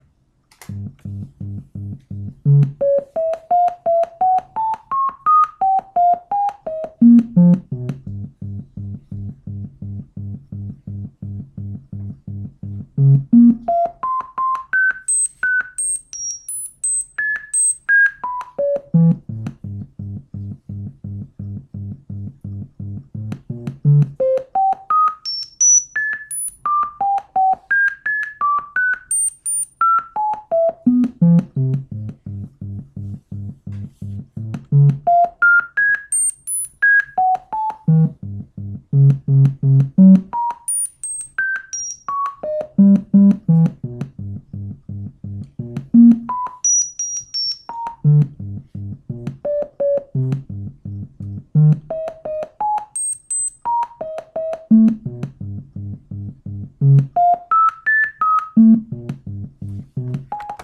The image has several bright spots of large dimensions which creates very high pitched sound – the wails of a star nearing its end.
The dark background between the ring the and exploding star provides the silences/reliefs between the sounds.